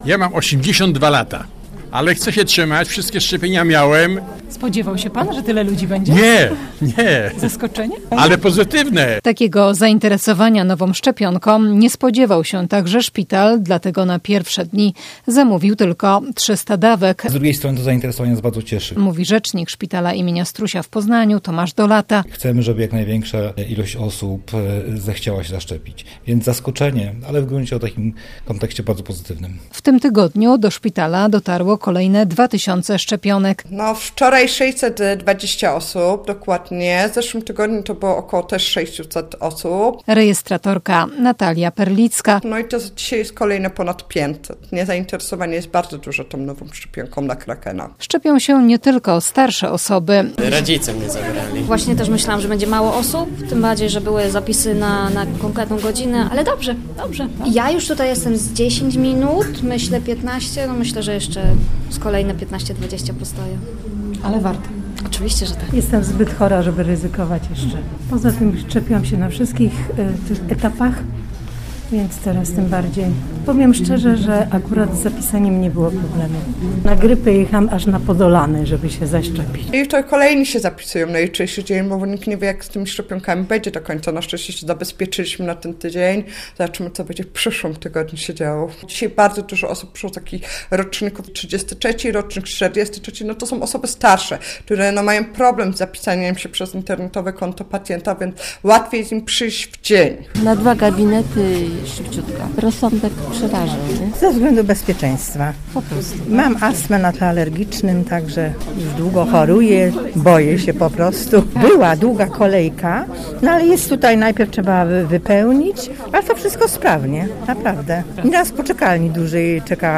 - mówili mieszkańcy,